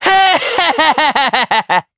Neraxis-Vox_Happy3_jp.wav